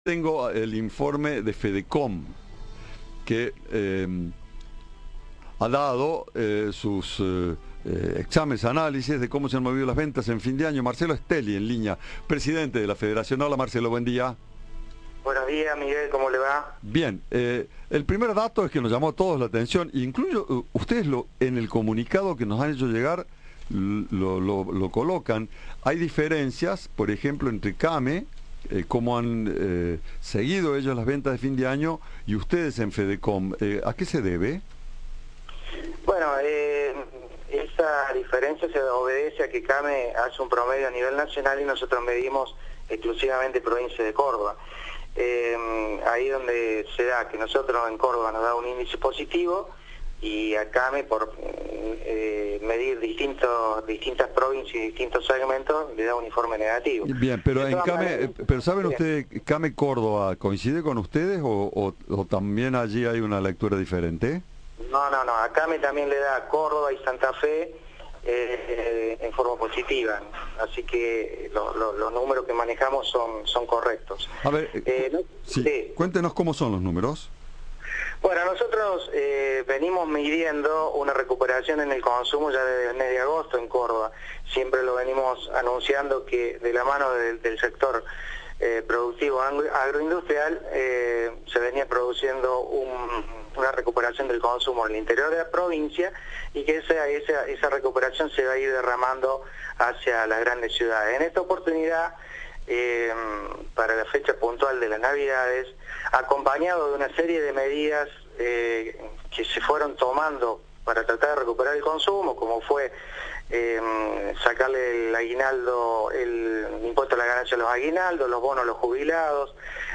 para radio Cadena 3 y explica el resultado positivo de las ventas navideñas en la provincia de Córdoba.